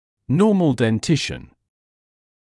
[‘nɔːml den’tɪʃn][‘ноːмл дэн’тишн]нормальный прикус